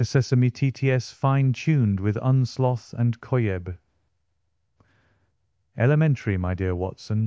Now comes the moment of truth, let’s test our deployed Gradio web app and hear Sherlock Holmes speak!
Within a few seconds, your custom Sesame TTS model will synthesize speech directly in the browser.